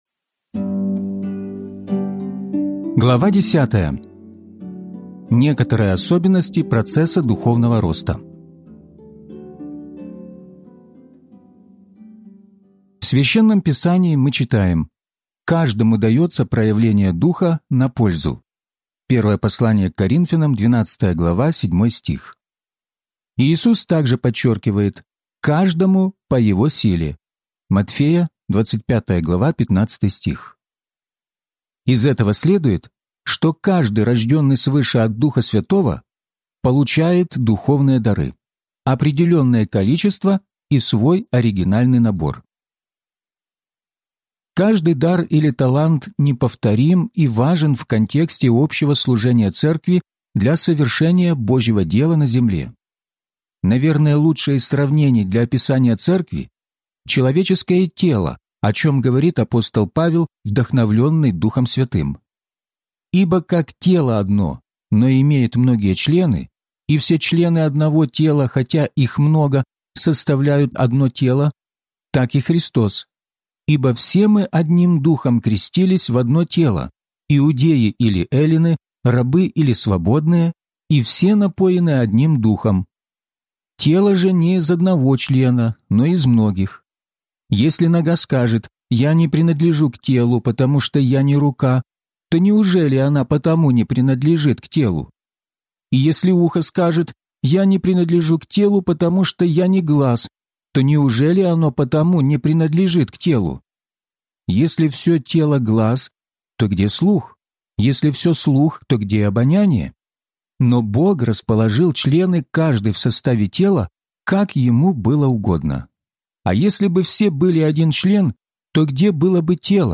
Возрастайте! (аудиокнига) - День 29 из 34